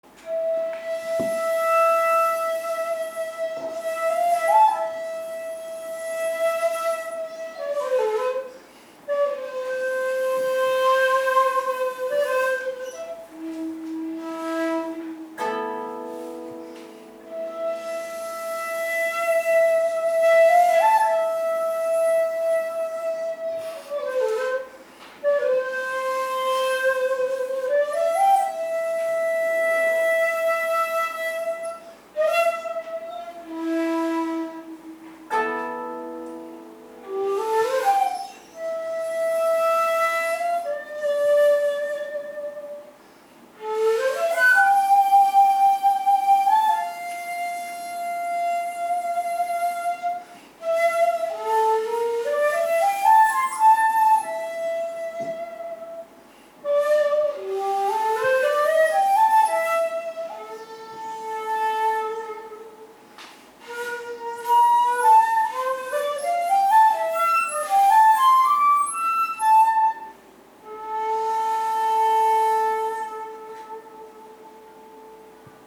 (a)　イントロ
ここは公園でブランコに乗っているようなテンポ感で元の拍の長さを大事にしながら流れるように吹きましょう。